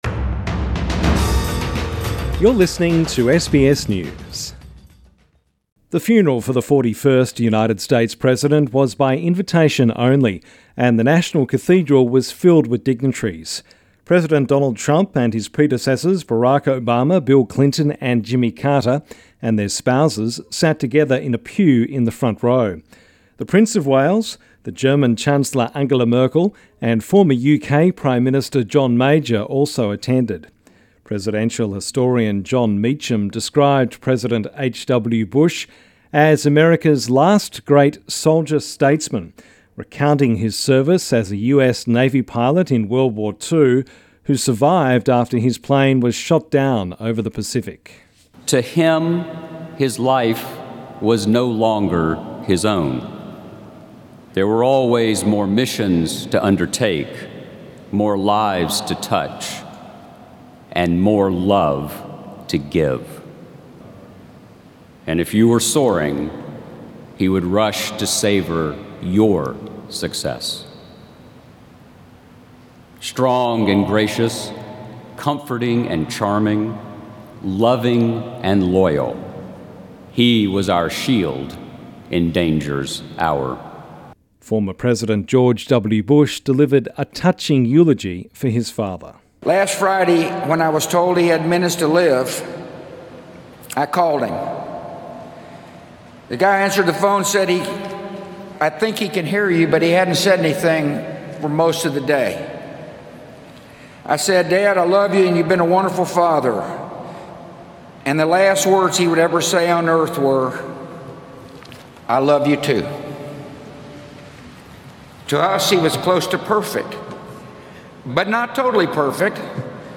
State funeral held in Washington for President George HW Bush